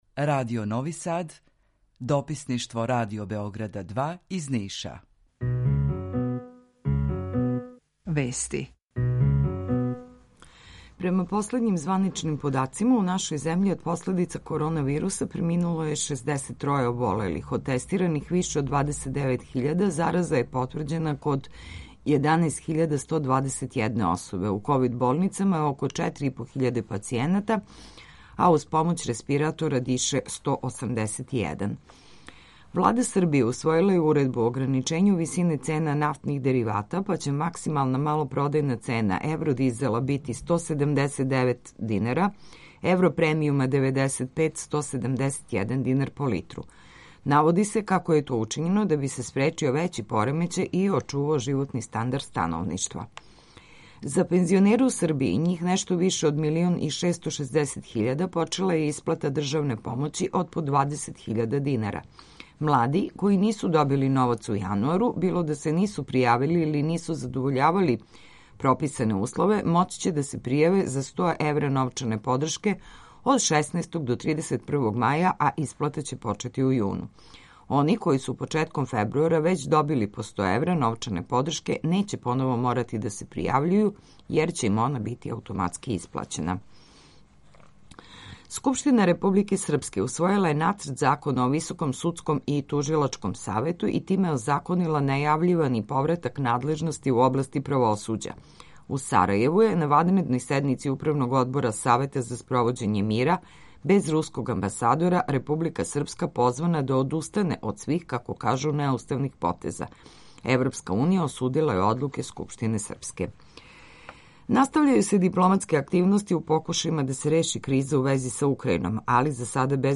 Емисију реализујемо заједно са студијом Радија Републике Српске у Бањалуци и Радијом Нови Сад
У два сата, ту је и добра музика, другачија у односу на остале радио-станице.